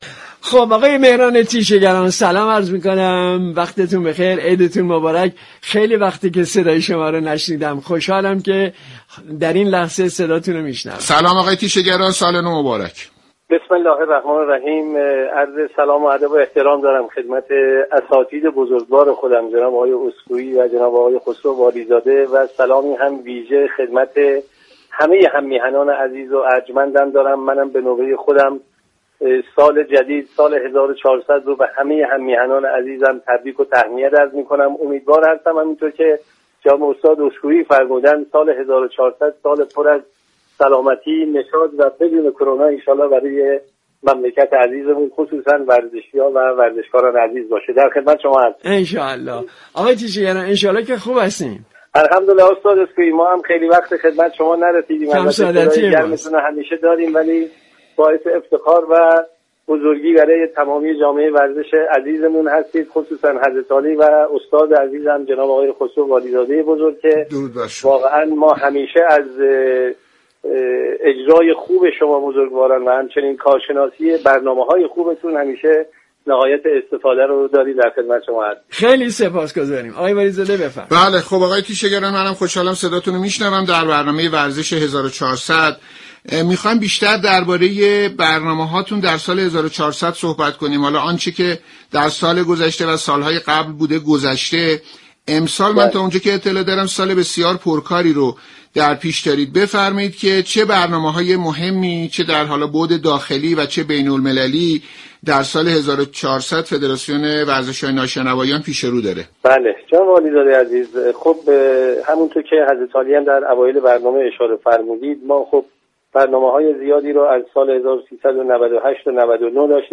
ویژه برنامه نوروزی «ورزش 1400» ایام نوروز از ساعت 14 به بررسی مسابقات مهم ورزشی سال 1400 رشته های مختلف می پردازد. این برنامه گفتگو محور